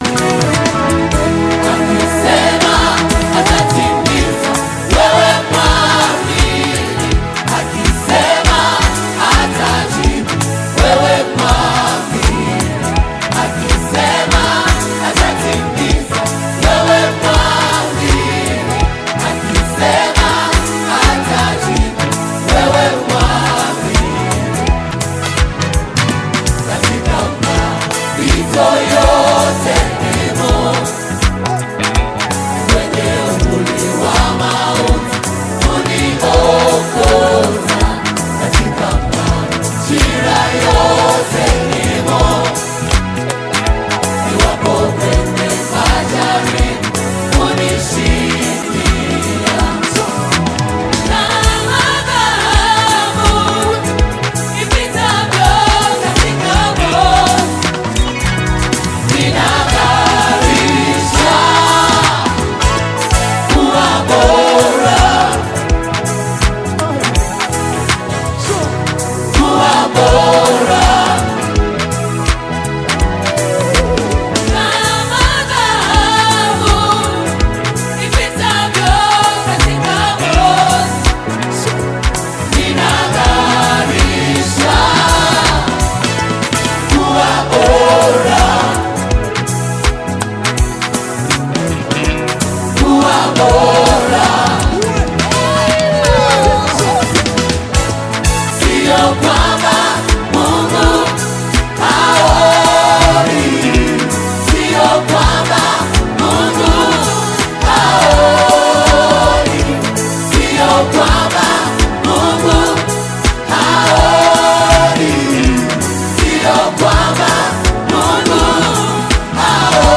International Gospel Songs
a gifted gospel singer and songwriter.